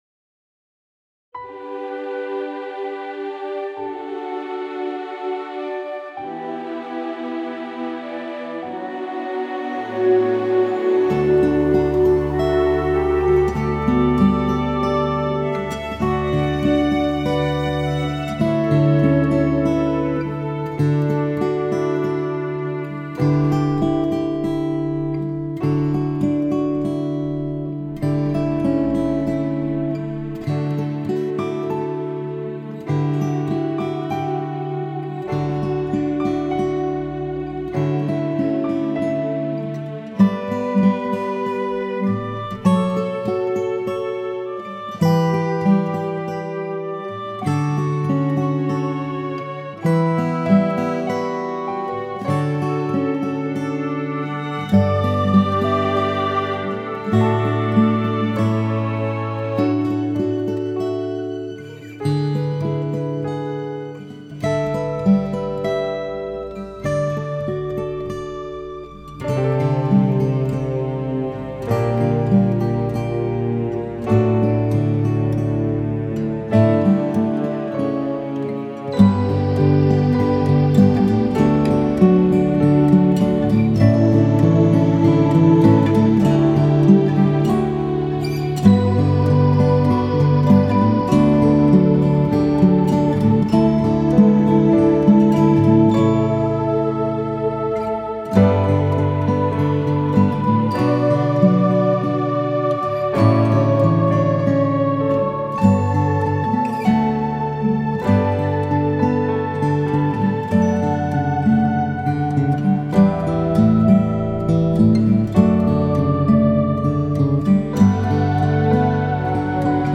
The new arrangements were magnificent – they were beautiful symphonies I enjoyed listening to while working on my paintings.
every-season-5-1-karaoke.mp3